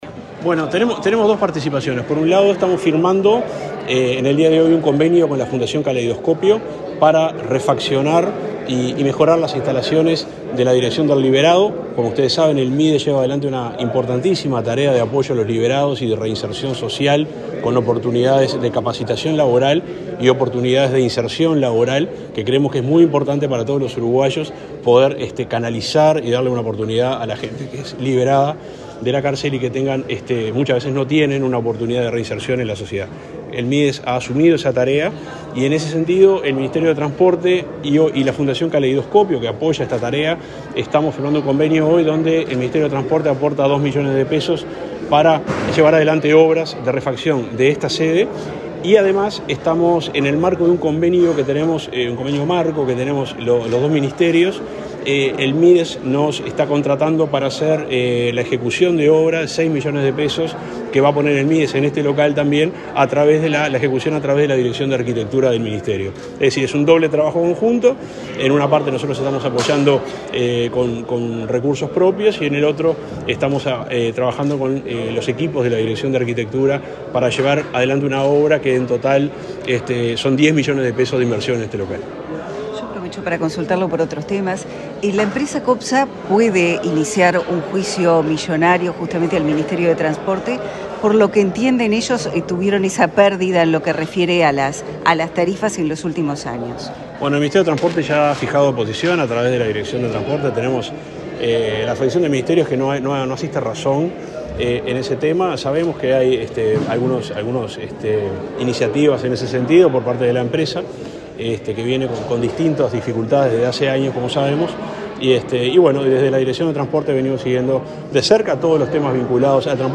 Declaraciones del subsecretario de Transporte, Juan José Olaizola
El subsecretario de Transporte y Obras Públicas, Juan José Olaizola, dialogó con la prensa, luego de presentar el proyecto de obra del Espacio de Capacitación y Oportunidades Sociolaborales (ECOS) de la Dirección Nacional de Apoyo al Liberado (Dinali) y firmar un convenio social con la fundación Caleidoscopio, colaboradora en el financiamiento de la edificación mencionada.